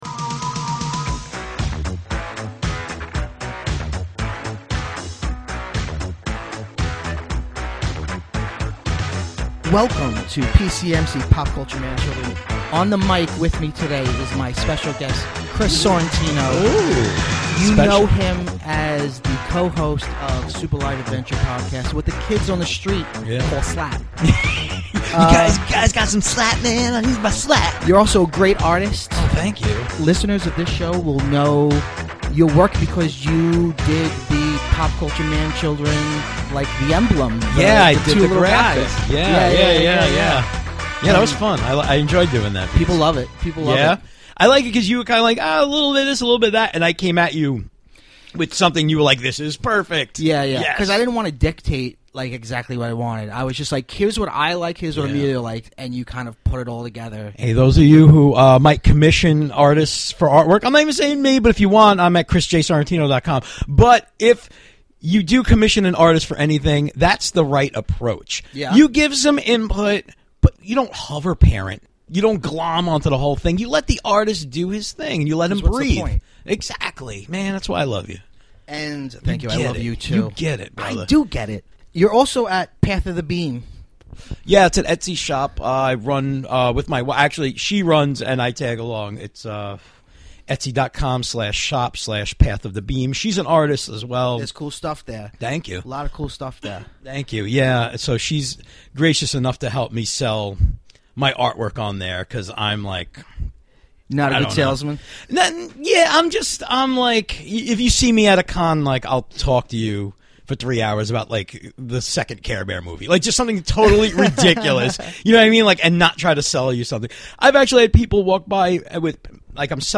The guys get deep and analytical about the impact Ghostbusters has on its younger viewers. And they also make a lot of dumb jokes and bad impressions!